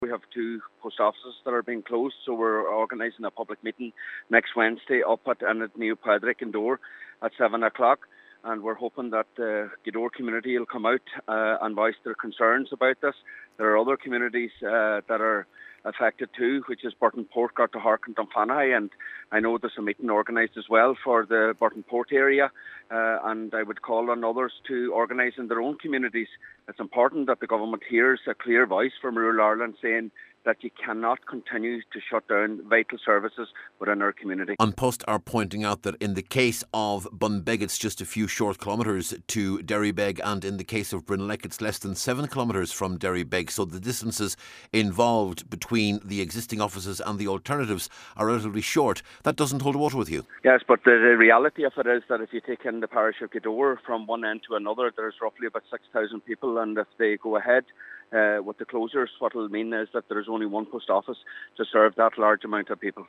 Local Councillor Michael Cholm Mac Giolla Easbuig says it’s vital that local people send a clear message that they want to retain services…………